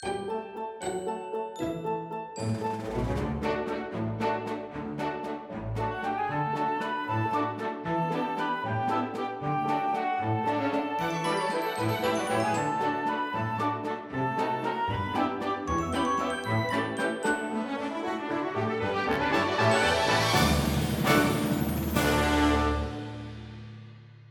A song
Ripped from game